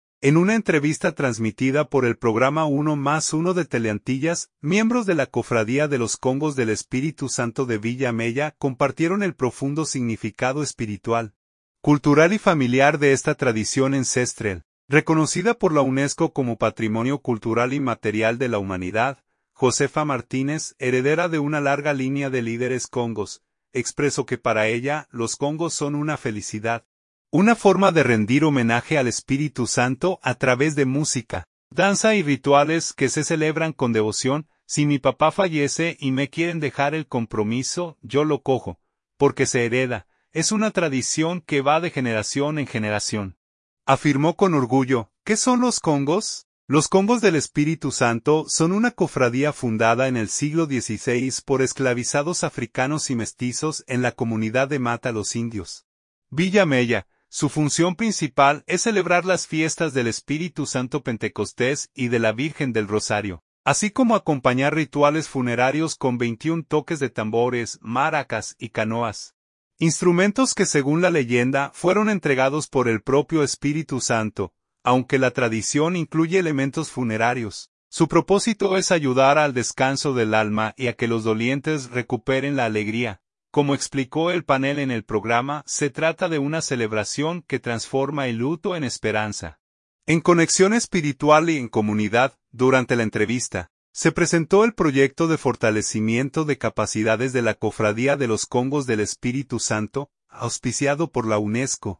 En una entrevista transmitida por el programa Uno + Uno de Teleantillas, miembros de la Cofradía de los Congos del Espíritu Santo de Villa Mella compartieron el profundo significado espiritual, cultural y familiar de esta tradición ancestral, reconocida por la UNESCO como Patrimonio Cultural Inmaterial de la Humanidad.